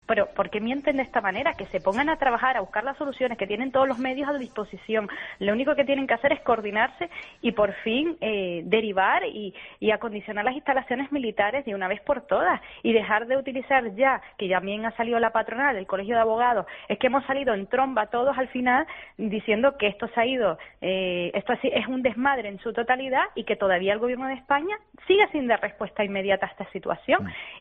Onalia Bueno, alcaldesa de Mogán
La alcaldesa de Mogán, Onalia Bueno, ha calificado en los micrófonos de COPE Canarias como “lamentable” la actitud y la actuación del ministro.